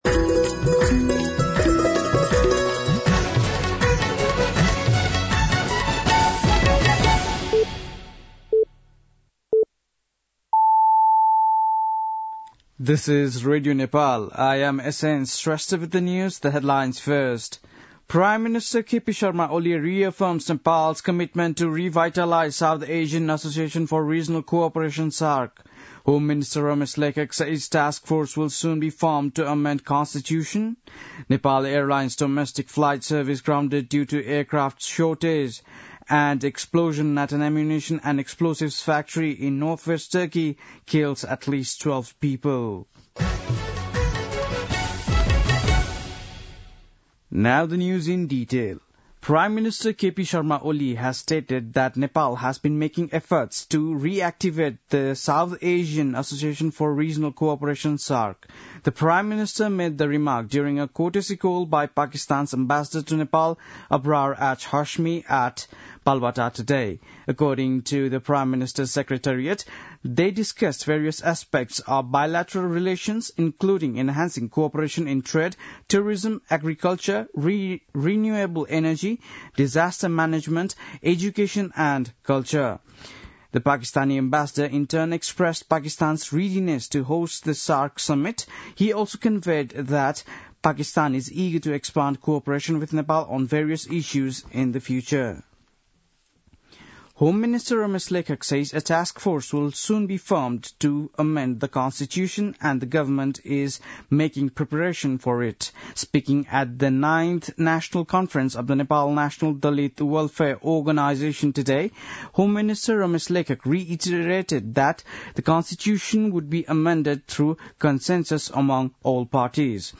बेलुकी ८ बजेको अङ्ग्रेजी समाचार : १० पुष , २०८१
8-PM-English-NEWS-9-09.mp3